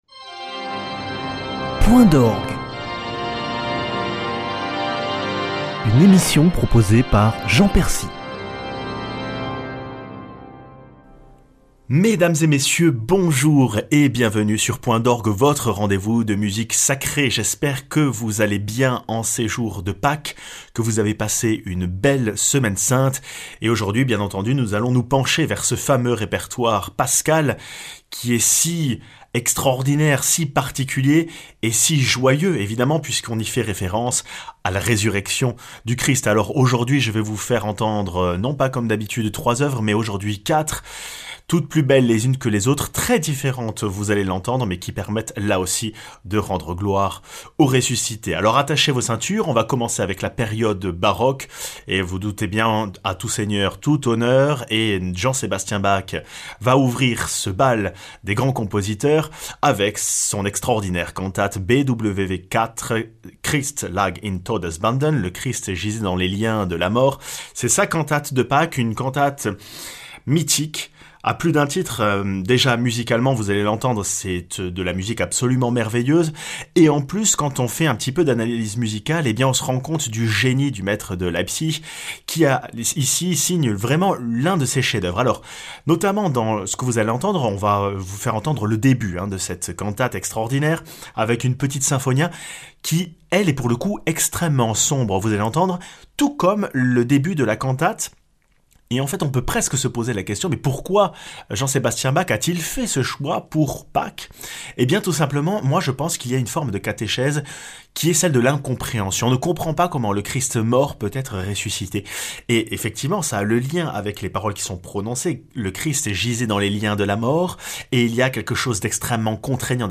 La résurrection du Christ donne aux compositeurs la joie d'écrire de la musique parfois explosive, contrastant avec l'intériorité du Carême. (BWV 4 de Bach, Exultate Deo de Poulenc, Ye choirs of New Jerusalem de Stanford et Gloria de la Missa Brevis de Dove)